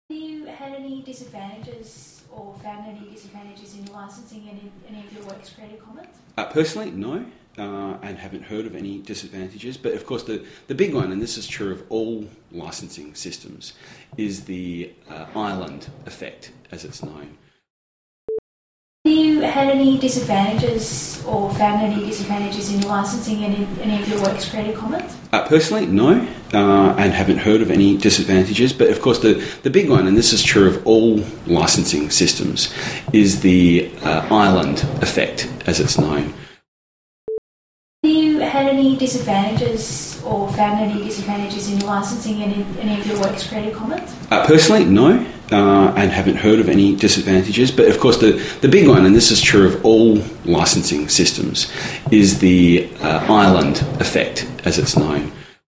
It responds quicker : the changes in gain are less obvious, less pumping/breathing, e.g. attached LevelSpeech2.NY (2.55 KB) /uploads/default/original/2X/4/4fbec0cf3bdfbce1030ce65bfb1b2ad51220b733.mp3